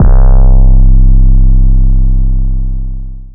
808s